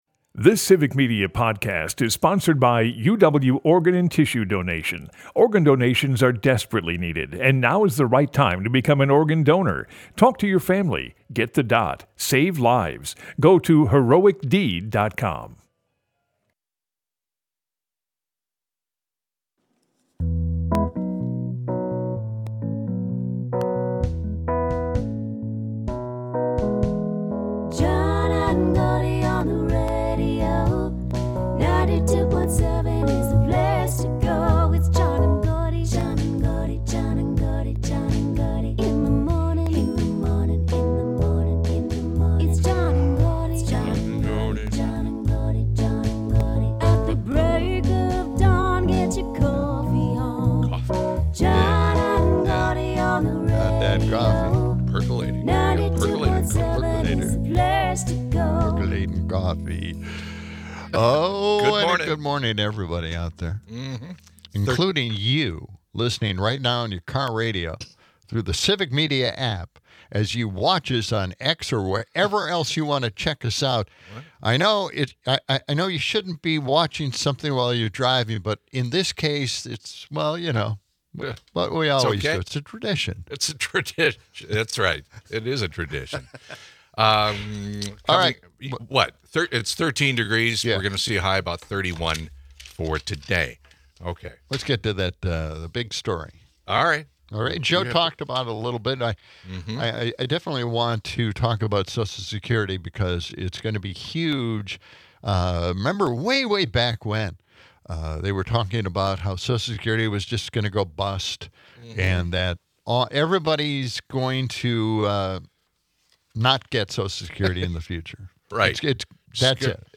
Broadcasts live 6 - 8am weekdays in Madison.